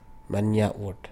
munn-yuh'-woort
IPA [ manyaʔwuɖ ]